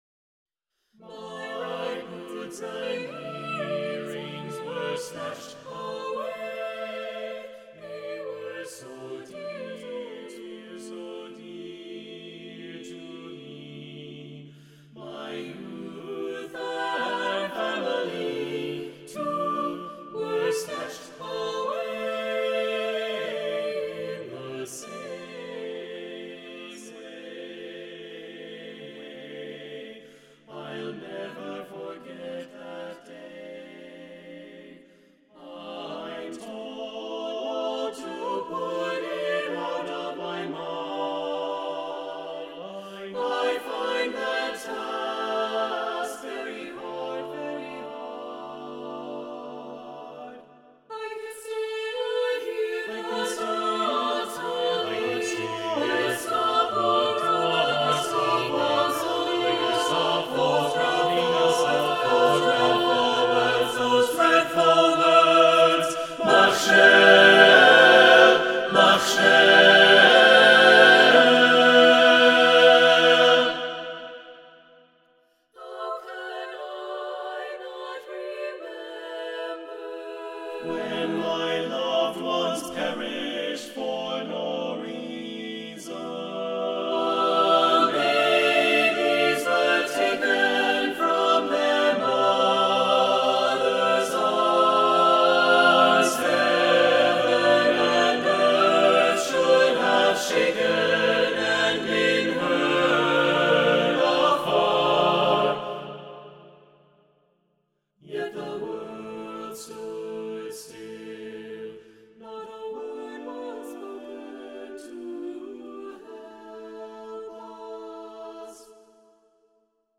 for mixed chorus: world premiere recording